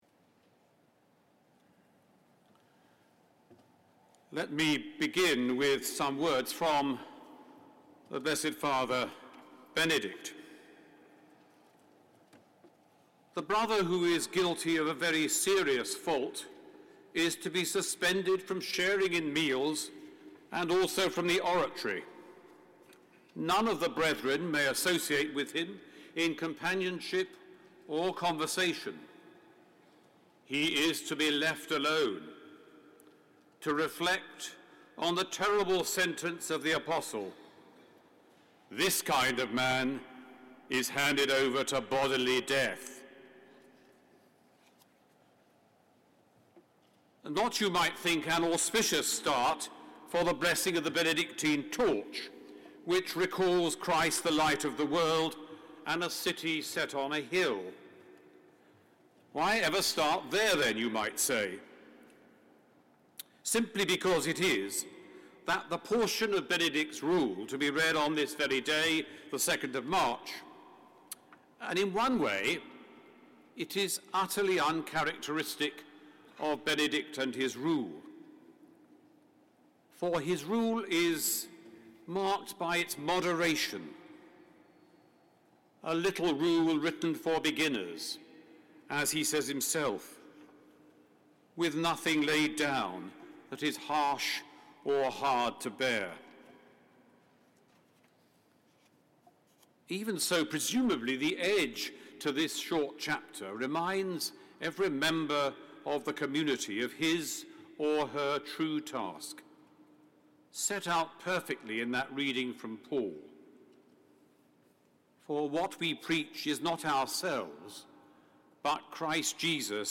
An Address given at A Service of blessing for the Benedictine Torch
Address given on 2nd March 2011 by the Right Reverend Stephen Platten, Bishop of Wakefield and Chairman of Governors, The Anglican Centre in Rome.